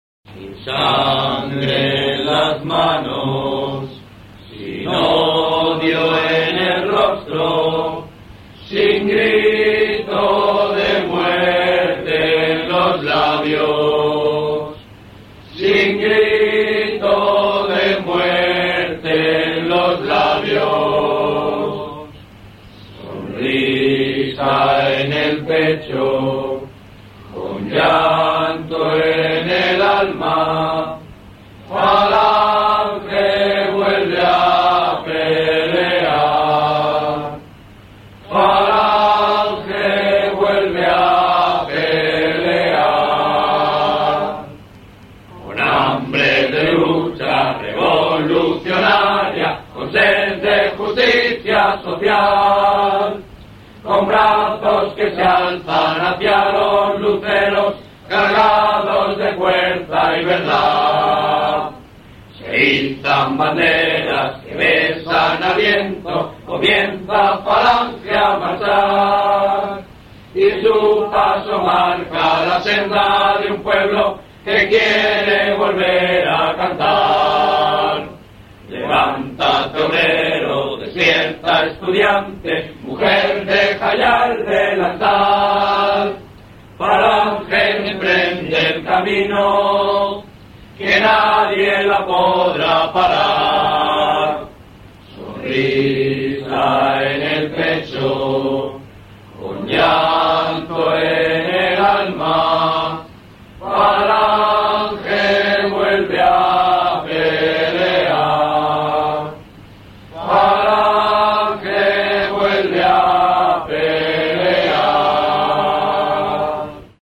Seleccione una versi�n y pulse sobre para escuchar Coro Hispanidad (a pleno pulm�n) Campamento Loma Riviellas 2001 (a pleno pulm�n)